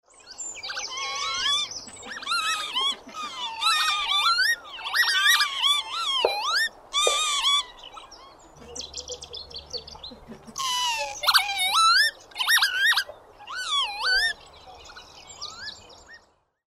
Northern Lapwing  Vanellus vanellus
Sonogram of Lapwing in aerial display
Rainham Marshes, Essex, England  51º 29' 15.35'' N  00º 13' 22.7'' E   1 May 2016
Territorial song flight.